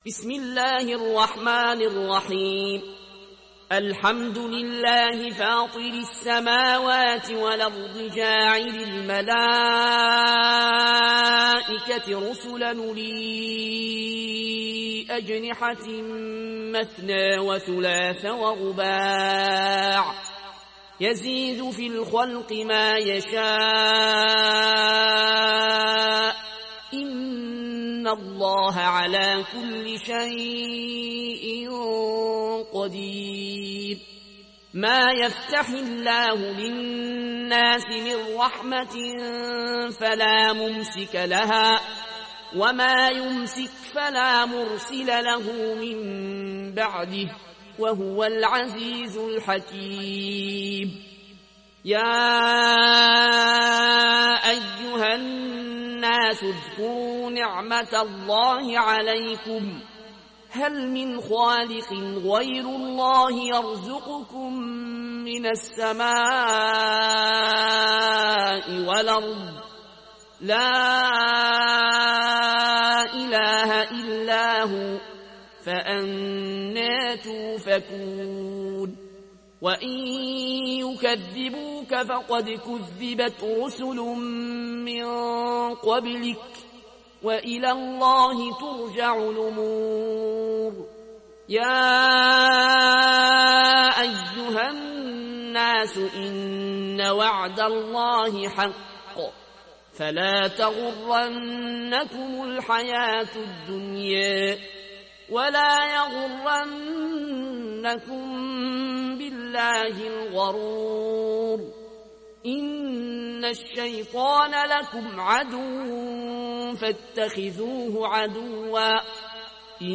উপন্যাস Warsh